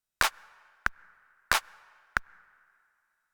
14 Clapping and Counting Basic Rhythms
Half notes.